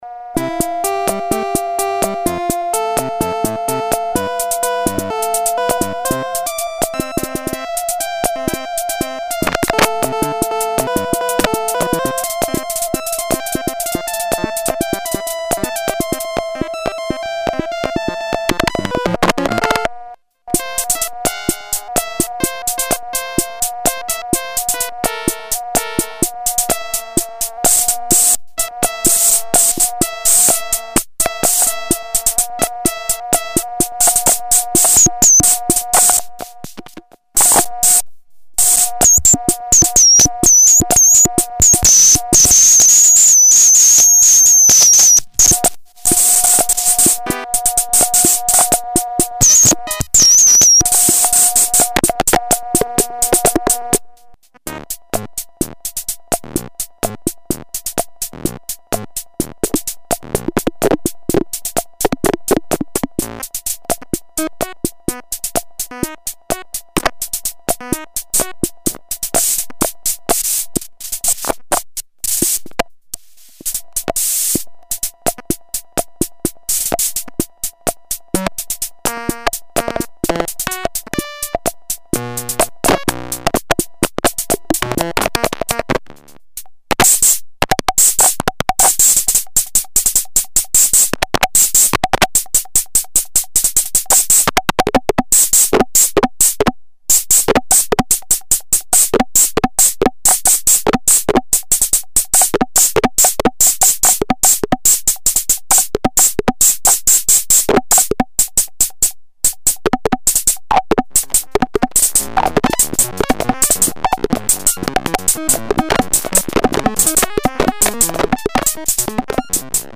An exercise in circuit bending:
Computer keyboard controls on short circuits within the donor keyboard did not work as well as anticipated, yet the various musical farts and analog drum sounds are divine.
music from the noisey machine
noisy machine.mp3